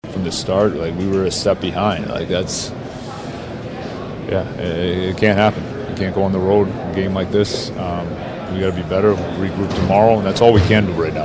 Coach Dan Muse says the Penguins weren’t sharp from the start.